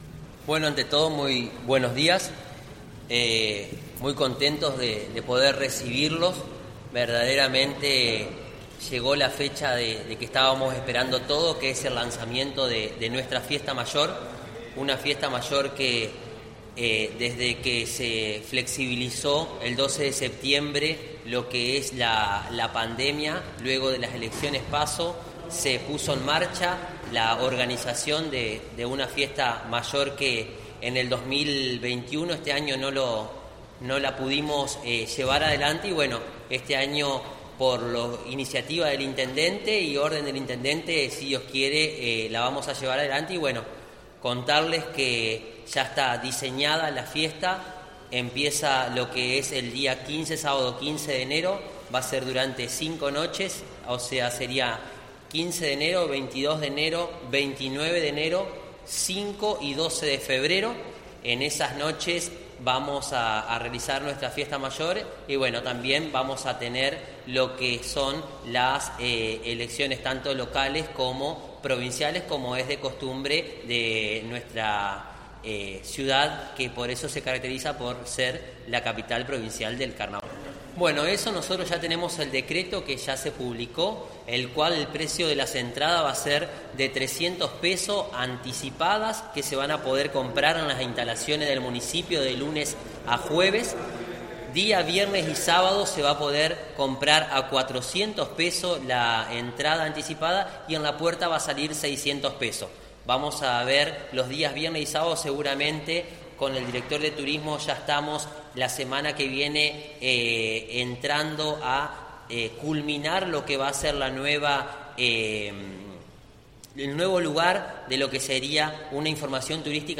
En la conferencia de prensa que se llevó a cabo esta mañana, el secretario de Gobierno Pedro Mansilla destacó que se está comenzando a trabajar en la instalación de un nuevo puesto de informes de la oficina de Turismo, el que estará ubicado en el quincho de los bungalós de la costanera.
Pedro Mansilla – Secretario de Gobierno